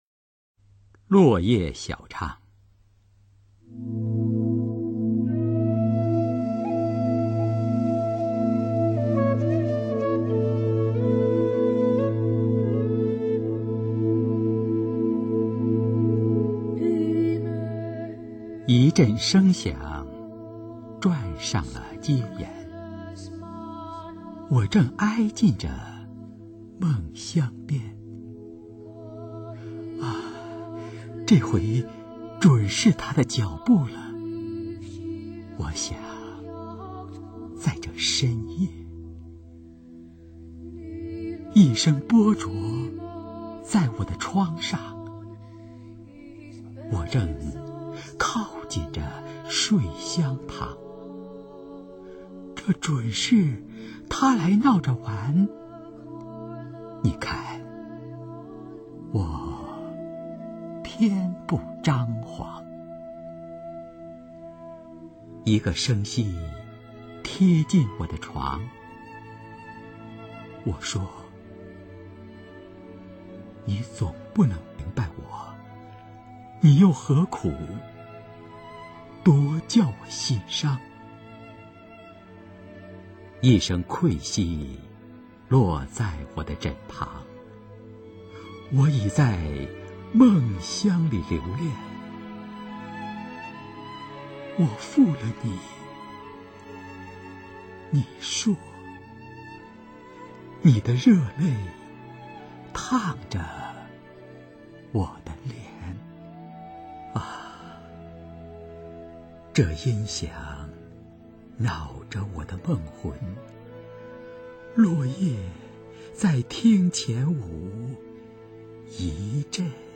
首页 视听 经典朗诵欣赏 中国百年经典诗文配乐诵读：光与色、情与景、人与事